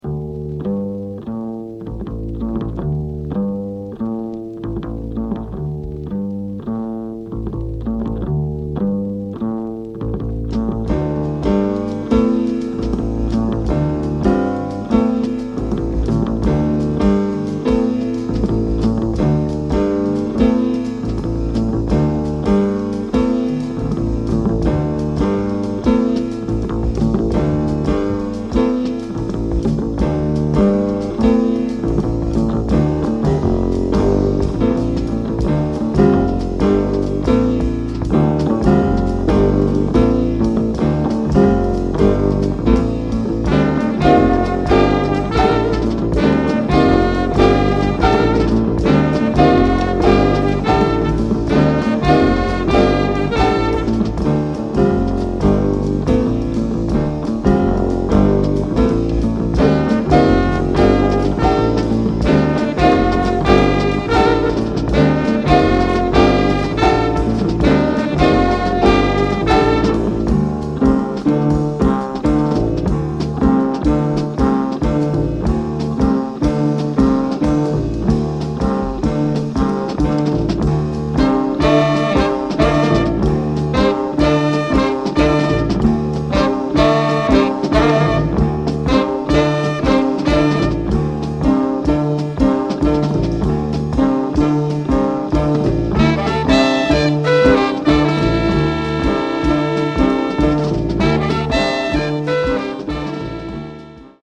spiritual jazz